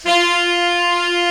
Index of /90_sSampleCDs/Giga Samples Collection/Sax/SAXIBAL LONG
TENOR SOFT F.wav